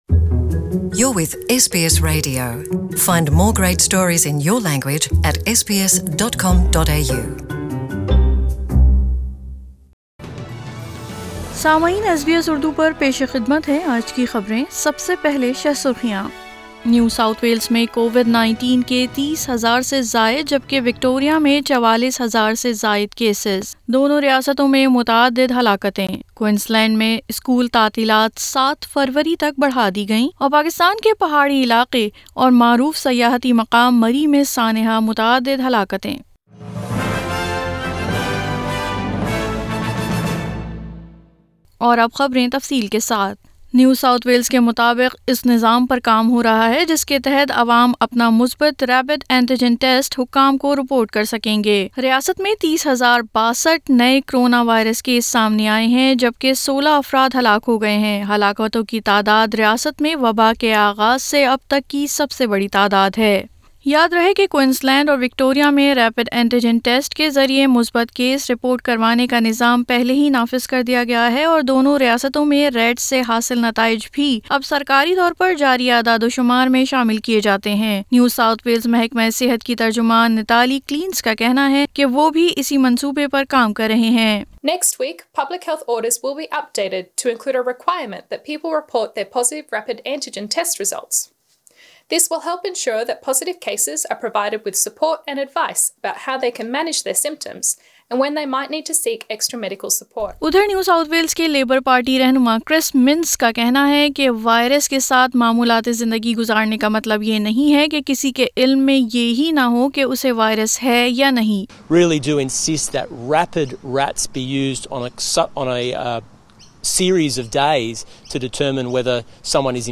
SBS Urdu News 09 January 2022